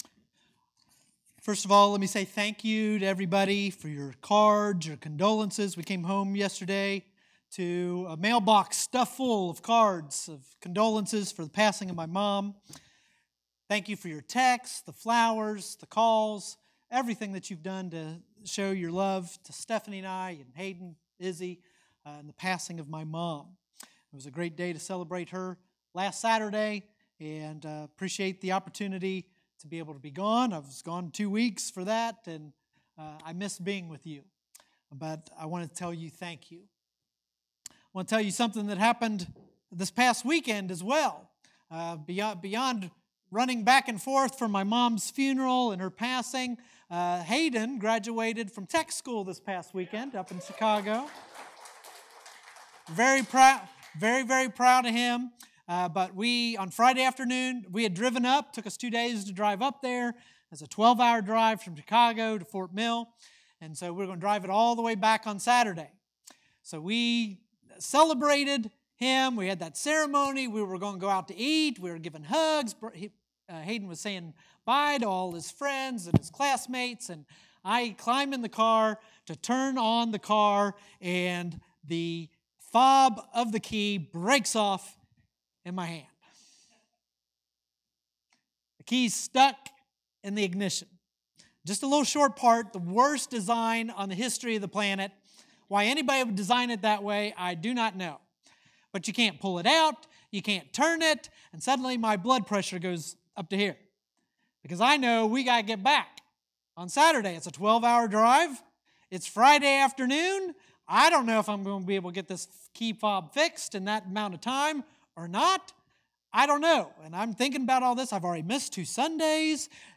Sermons | Flint Hill Baptist Church